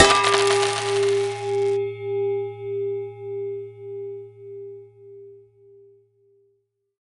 item sold.ogg